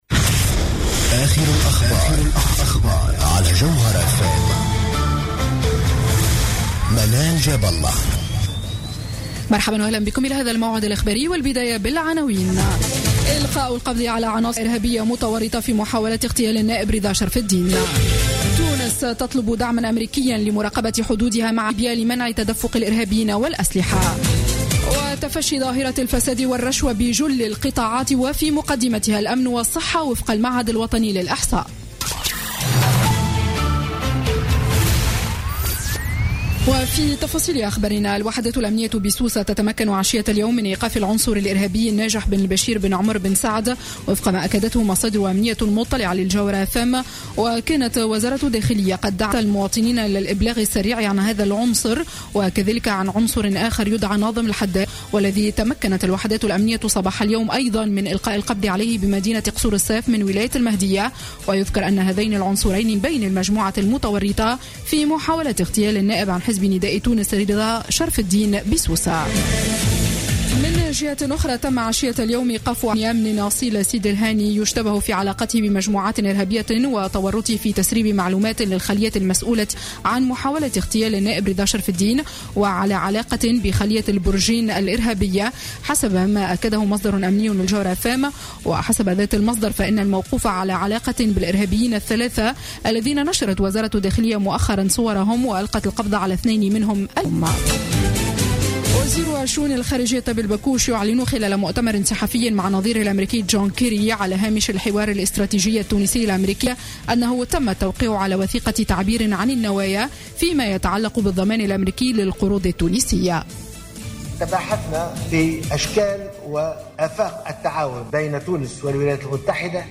نشرة أخبار السابعة مساء ليوم الجمعة 13 نوفمبر 2015